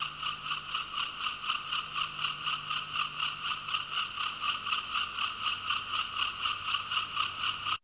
NIGHT ATMOS.wav